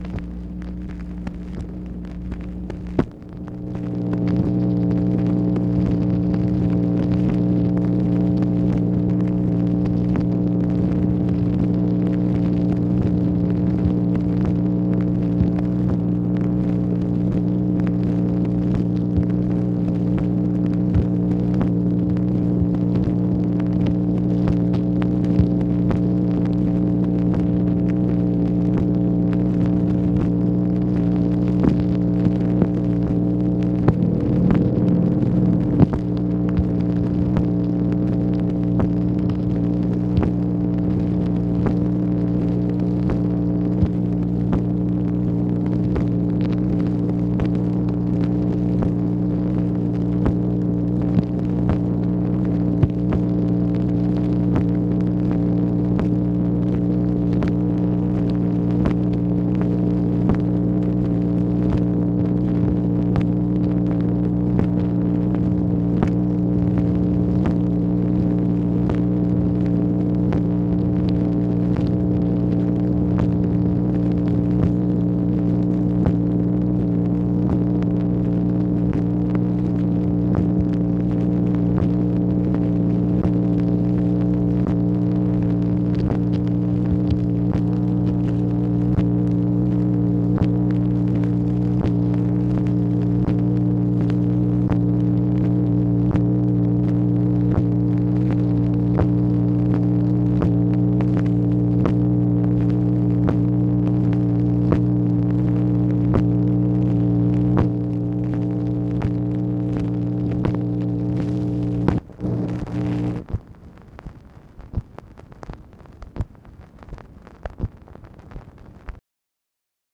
MACHINE NOISE, August 1, 1964
Secret White House Tapes | Lyndon B. Johnson Presidency